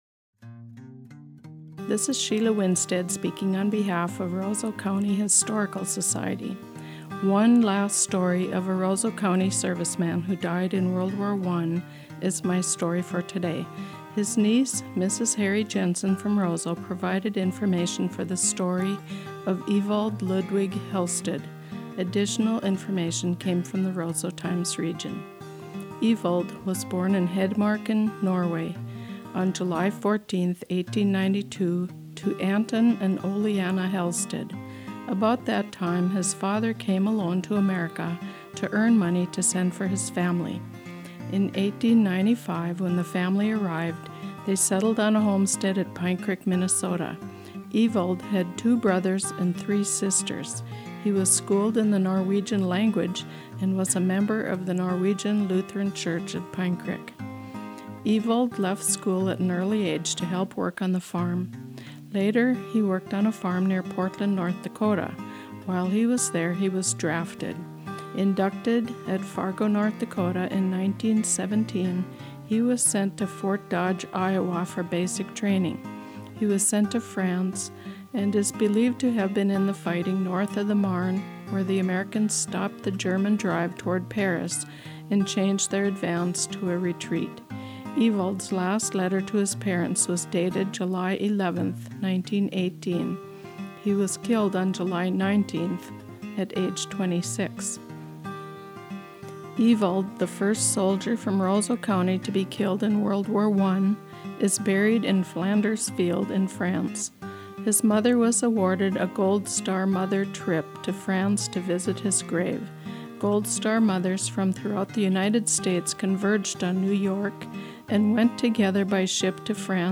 Sunday Morning Radio Readings – June 2017